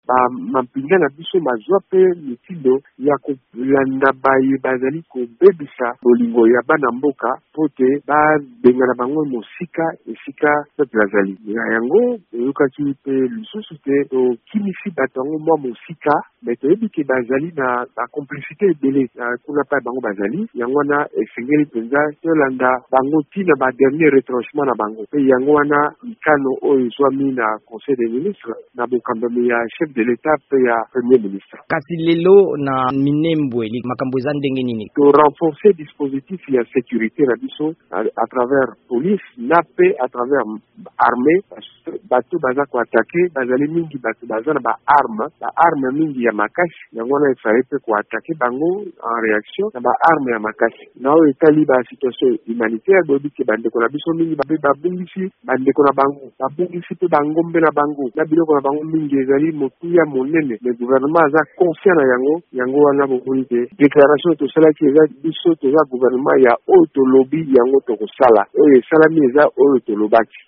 Eyano ya mbulamatari ya ekolo Congo démocratique nsima na botamboli ya ba Banyamulenge awa na Washington mpo na kopamela bobomami na bango na Minembwe, na Hauts plateaux ya Sud-Kivu. VOA Lingala ebengaki na nzela ya singa ministre molobeli ya mbulamatari ya Premier ministre Sylvestre Ilunga Ilunkamba, Jolino Makelele.